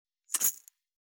350岩塩を振る,調味料,カシャカシャ,ピンク岩塩,
効果音厨房/台所/レストラン/kitchen